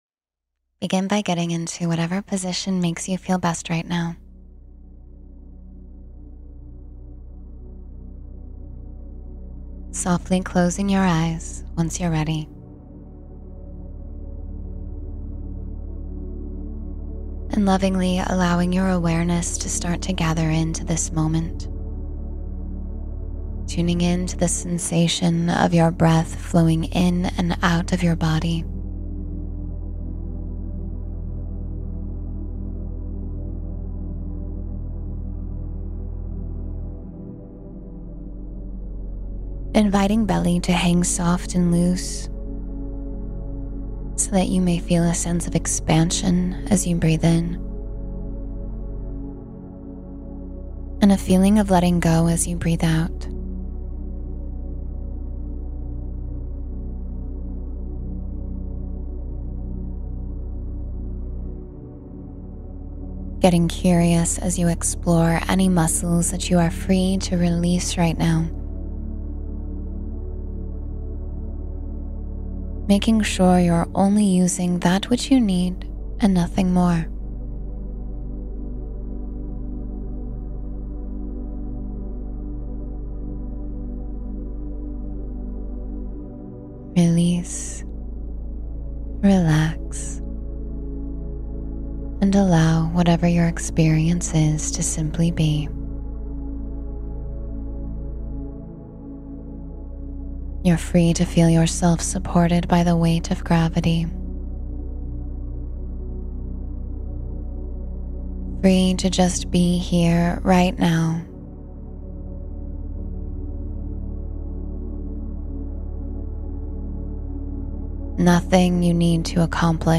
Journey Into Deep and Healing Relaxation — Guided Meditation for Stress Relief